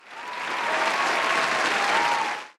Applause!